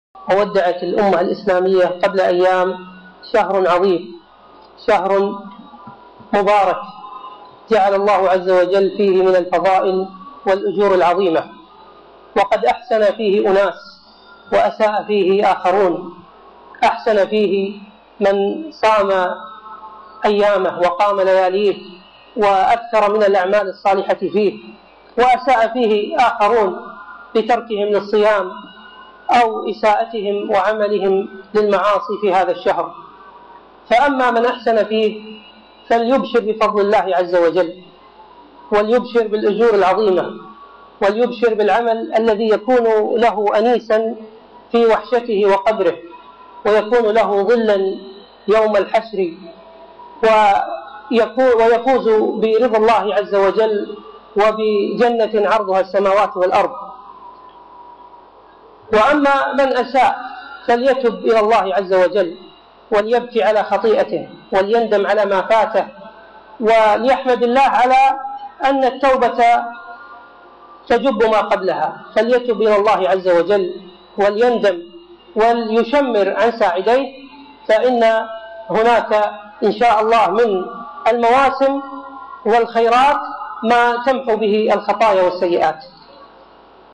موعظة ما بعد رمضان